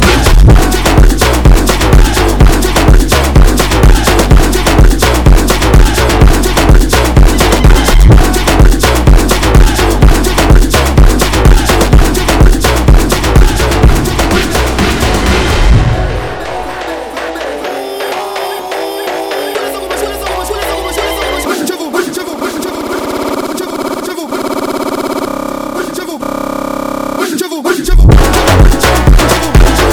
Жанр: Электроника
# Electronic